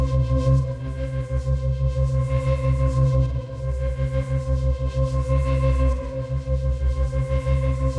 Index of /musicradar/dystopian-drone-samples/Tempo Loops/90bpm
DD_TempoDroneB_90-C.wav